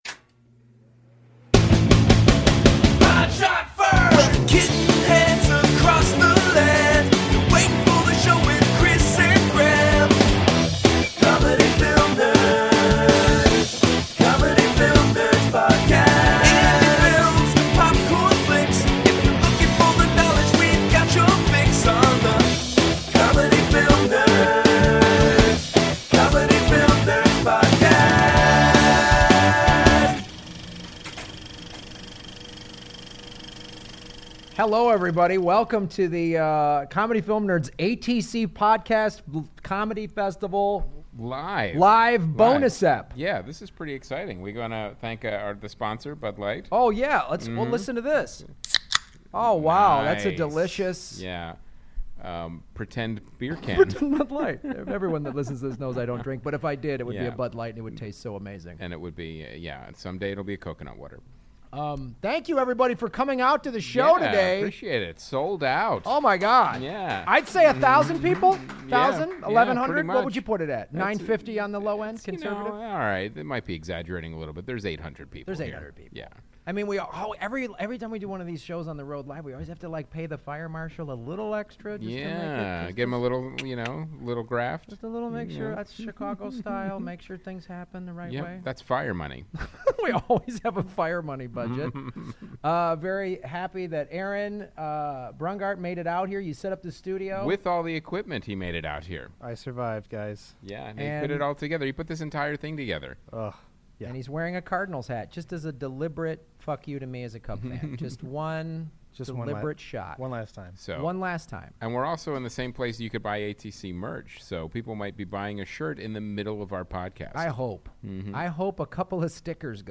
ATC Fest Live From Phoenix